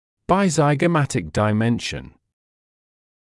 [baɪˌzaɪgə’mætɪk daɪ’menʃn][байˌзайгэ’мэтик дай’мэншн]расстояние между внешними контурами скуловых костей